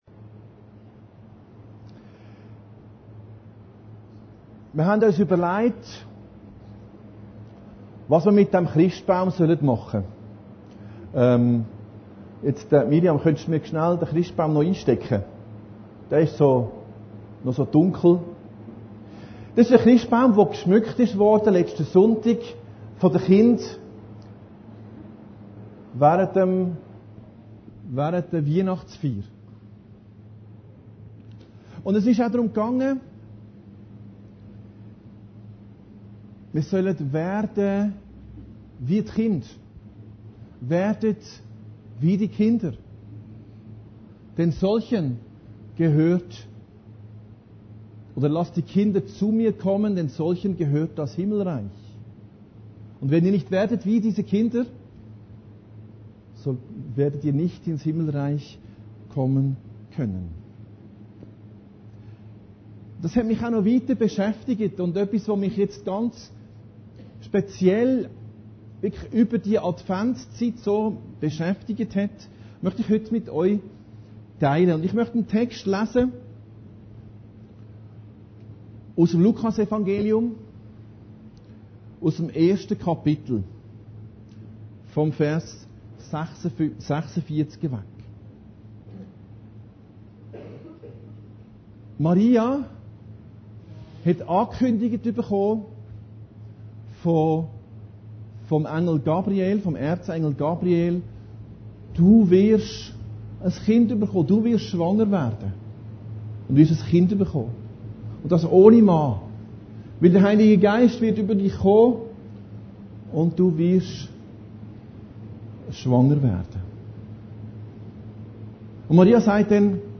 Predigten Heilsarmee Aargau Süd – Gott hat das niedrige gewhlt